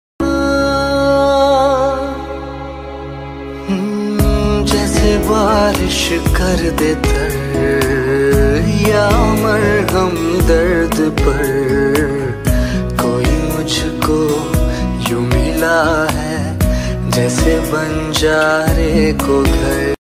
Categories Baarish Ringtones / Rain Ringtones